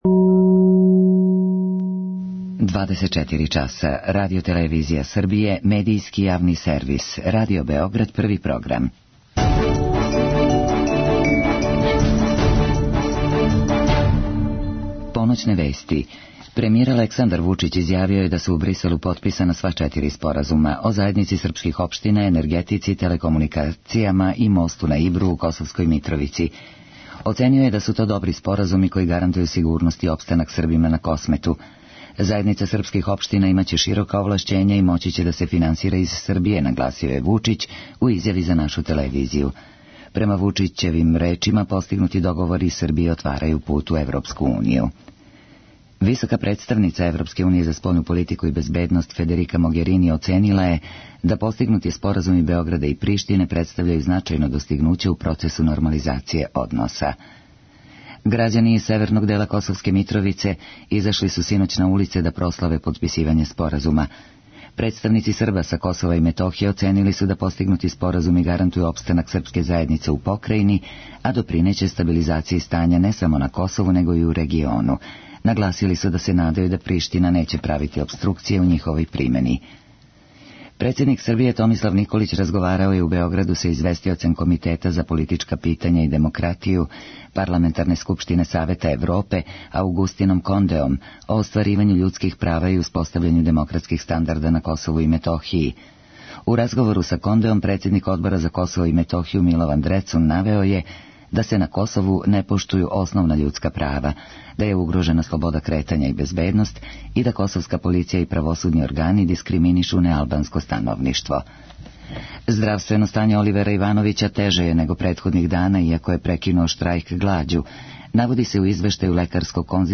Емисија представља неку врсту групног портрета писаца који су у том периоду боравили у Тршићу. Слушаћемо тонске записе разговора са писцима, начињене за време трајања колоније.